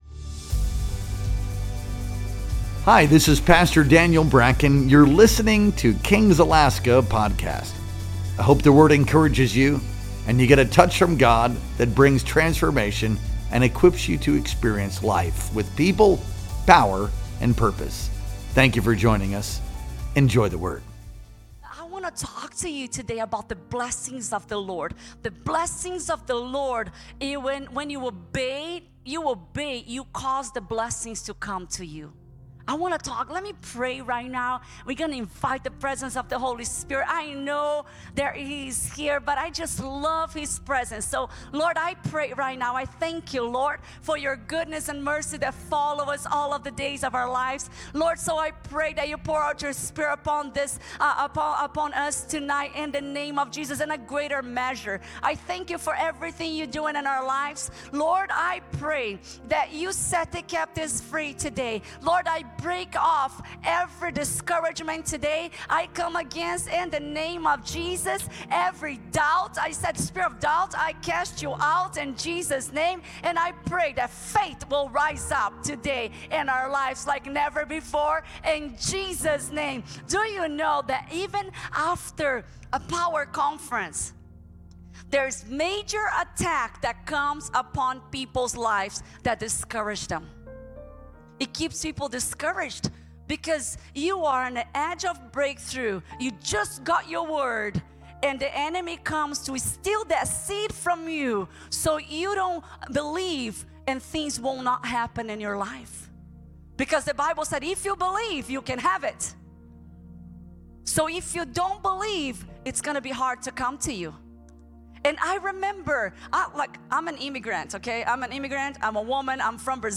Our Wednesday Night Worship Experience streamed live on February 19th, 2025.